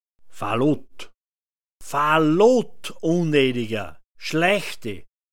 Falott [fa’lot] m